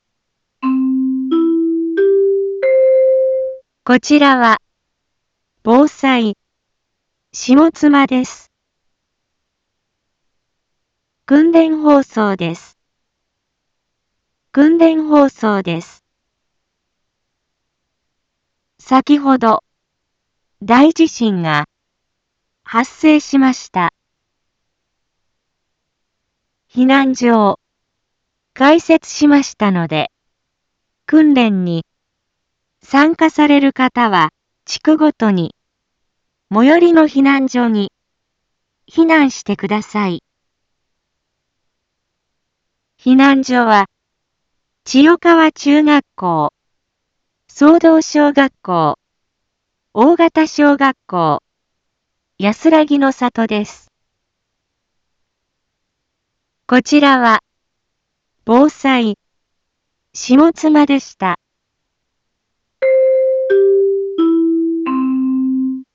一般放送情報
Back Home 一般放送情報 音声放送 再生 一般放送情報 登録日時：2023-11-26 07:31:07 タイトル：避難訓練実施について インフォメーション：こちらは、防災、下妻です。